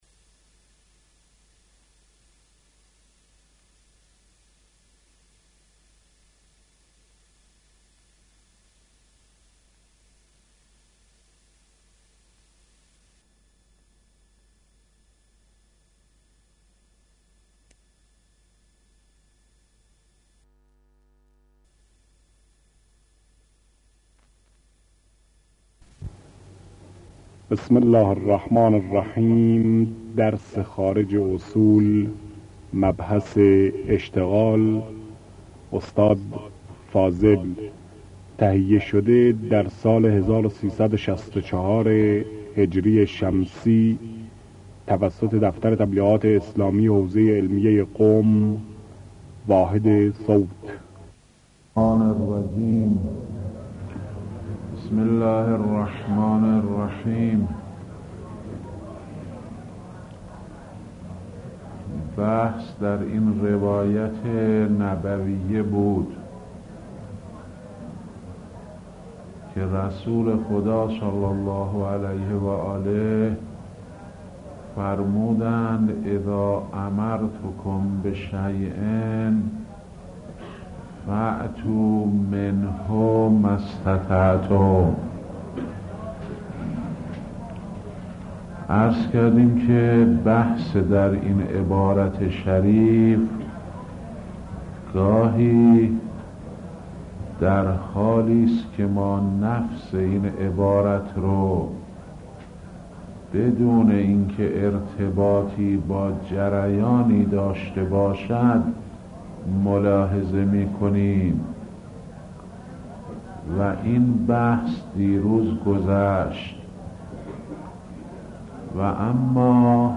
آيت الله فاضل لنکراني - خارج اصول | مرجع دانلود دروس صوتی حوزه علمیه دفتر تبلیغات اسلامی قم- بیان